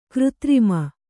♪ křtrima